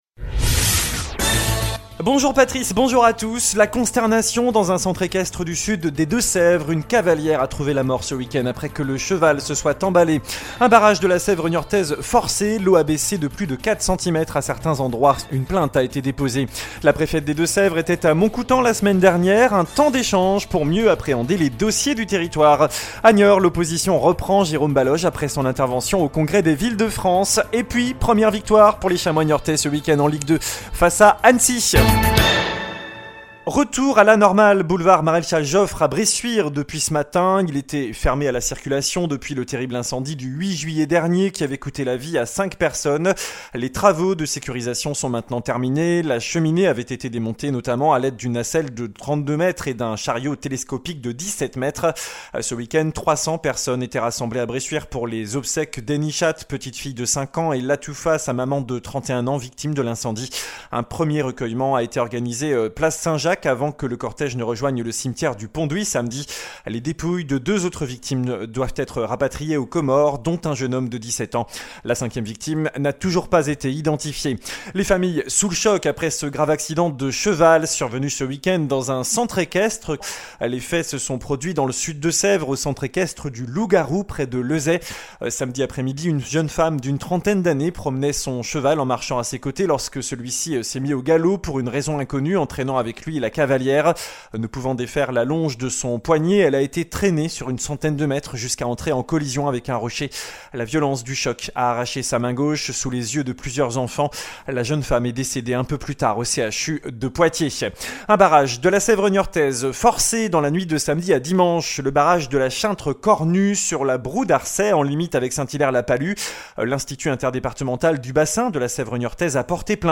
JOURNAL DU LUNDI 01 AOÛT